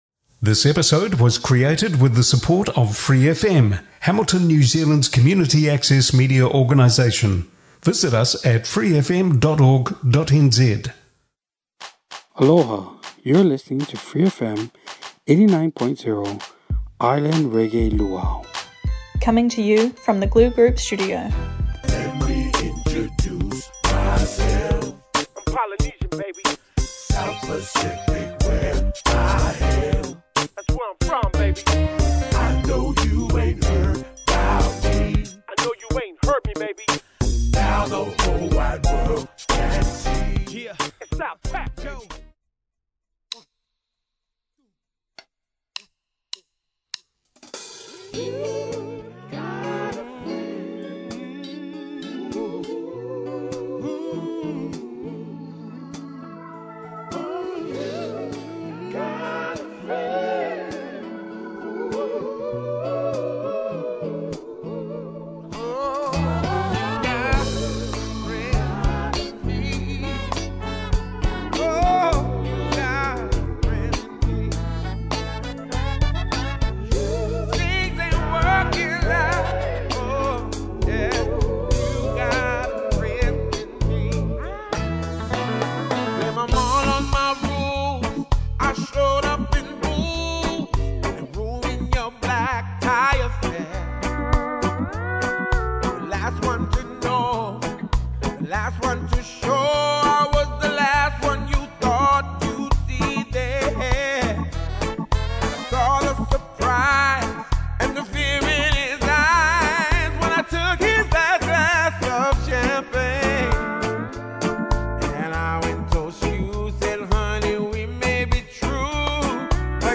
A feast of reggae, delivered in uniquely Hawaiian style.